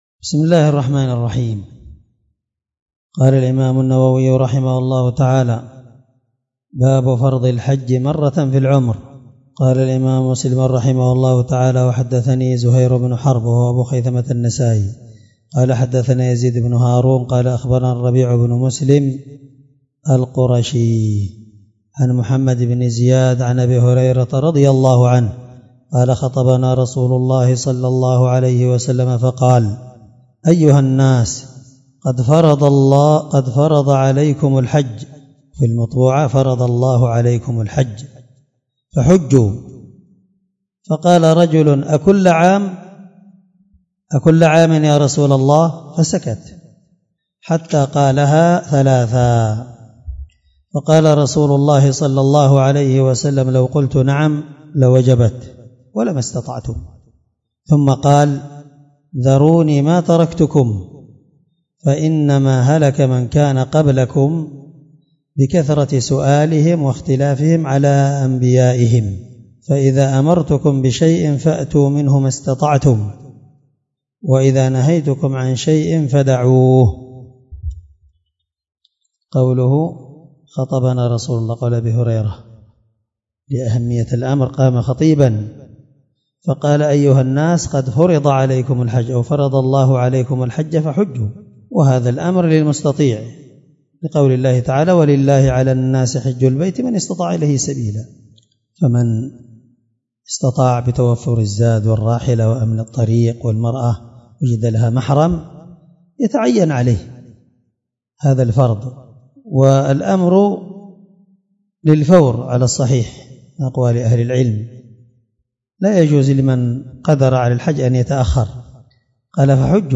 الدرس71من شرح كتاب الحج حديث رقم(1337) من صحيح مسلم